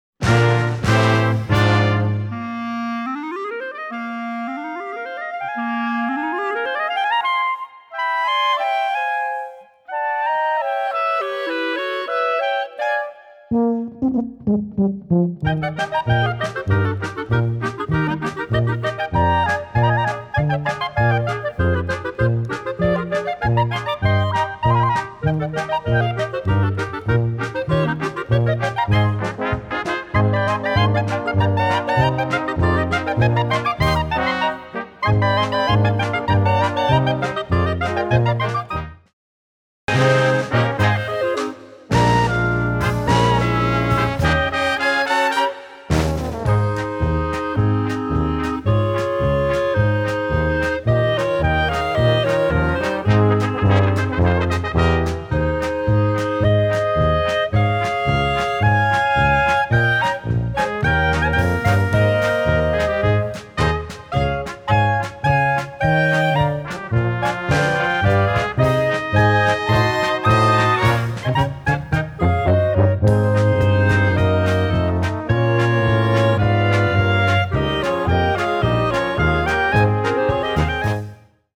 Blaskapelle